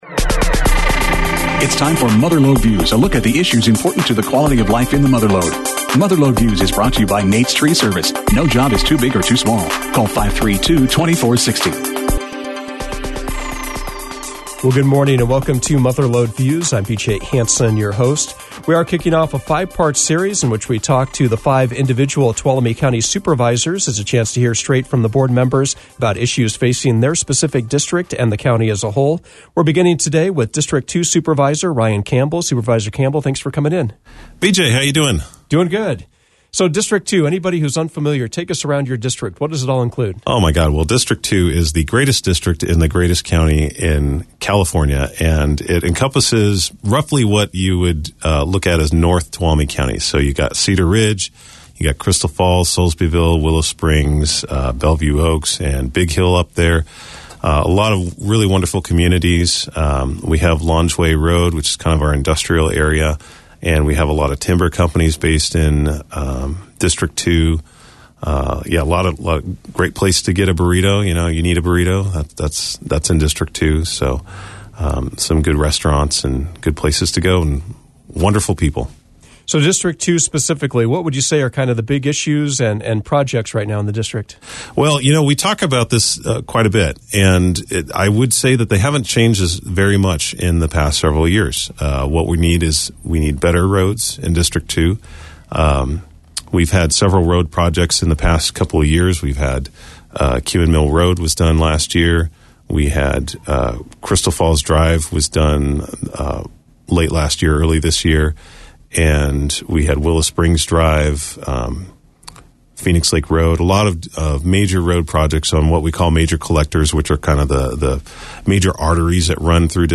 Mother Lode Views featured District Two Tuolumne County Supervisor Ryan Campbell.